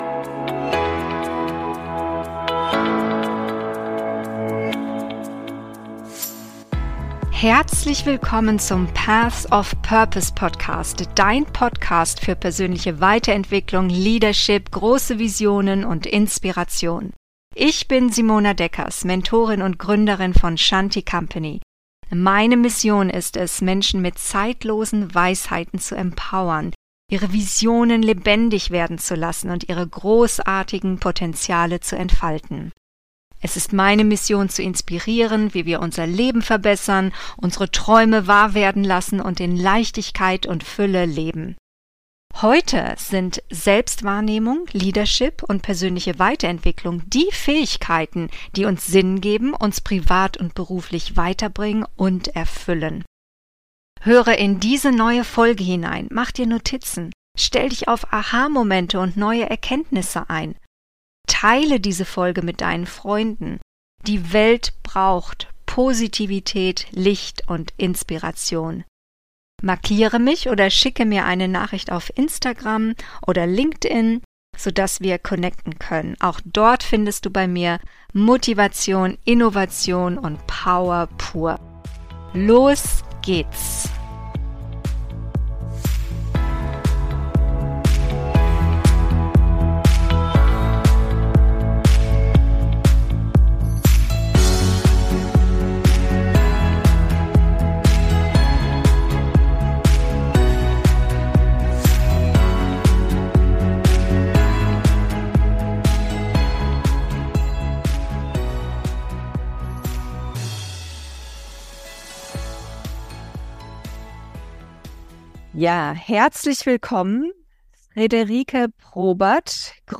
Mission Female: Für Frauen in Führung – Interview